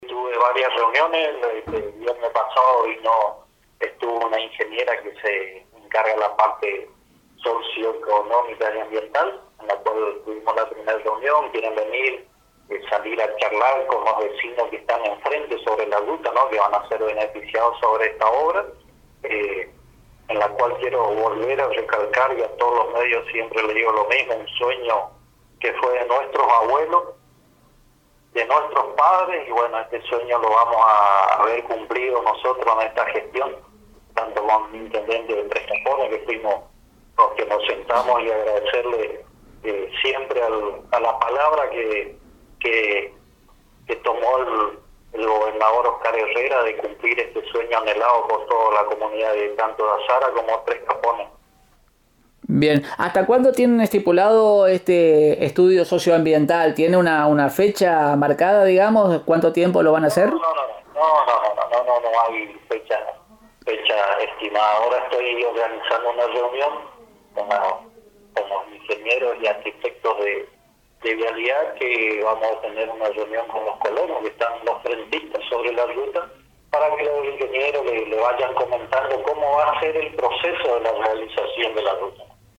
En comunicación telefónica con Radio Elemental y para A.N.G. sostuvo “tuve […]